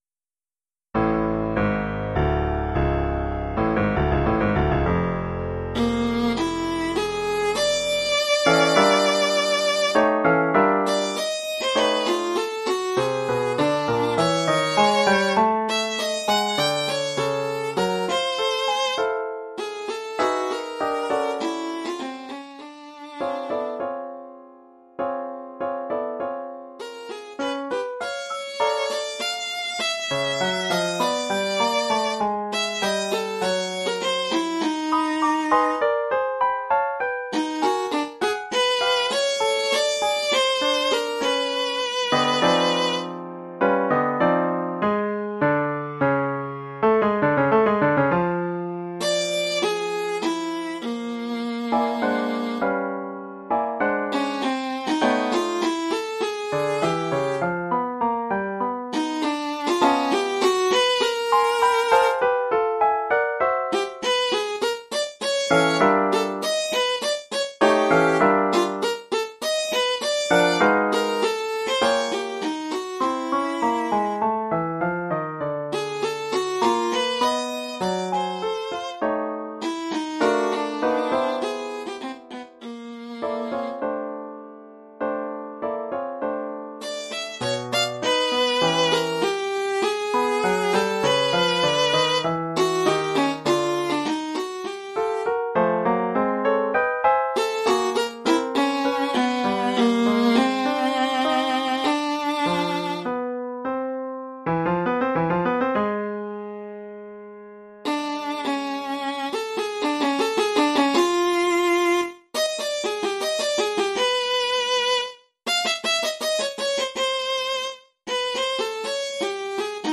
1 titre, alto et piano : conducteur et partie d’alto
Oeuvre pour alto et piano..